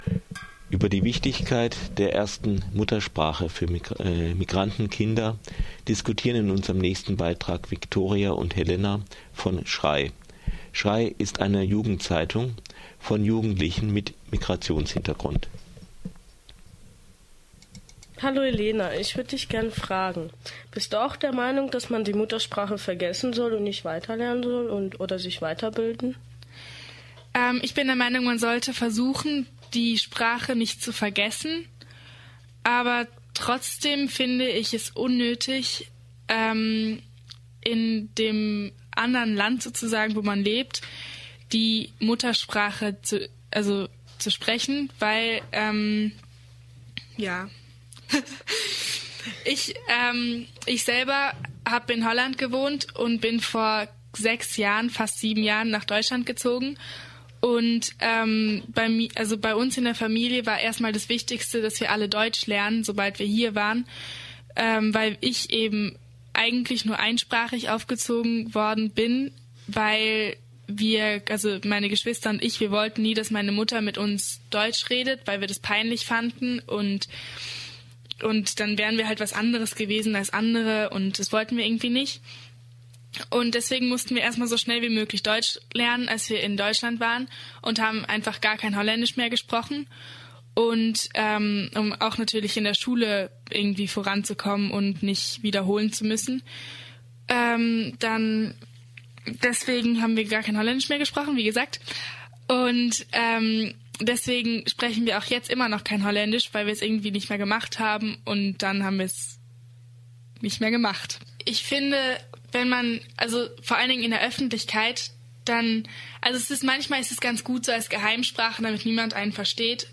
Zwei Mitarbeiterinnen der Jugendzeitung "Schrei", die sich vor allem an Jugendliche mit Migrationshintergrund richtet, diskutieren über die Vorzüge des Erlernens oder Behaltens der Muttersprache.